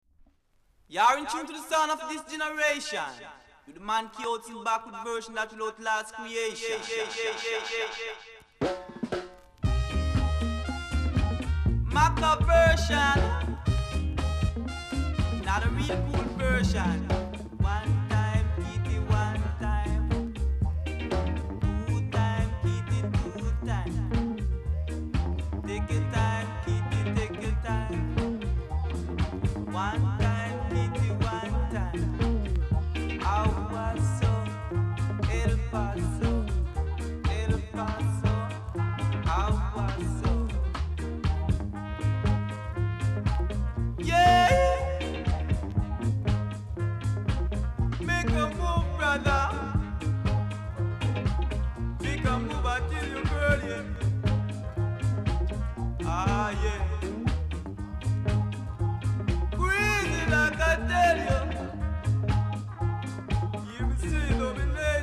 ※最後の方に傷あり、パチノイズ拾います。ほかA面同様です。